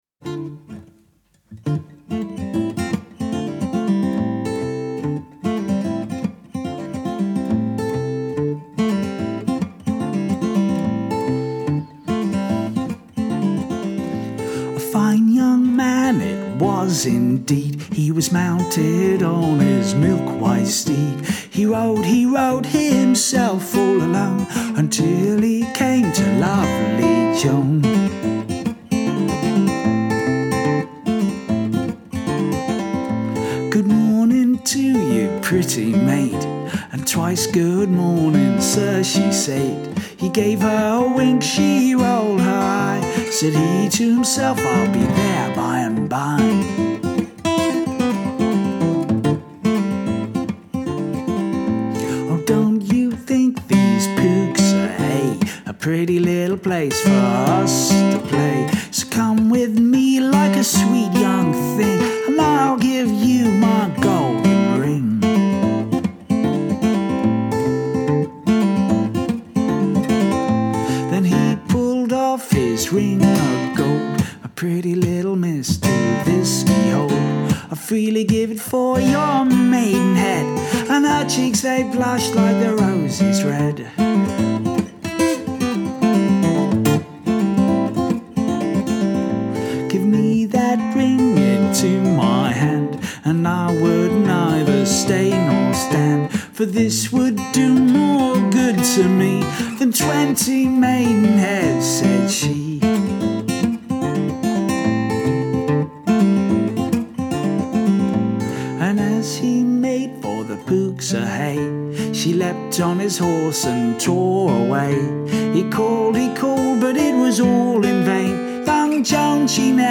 My current album, Constant Companion, is largely traditional songs.
All the songs on this album were performed live and are single takes.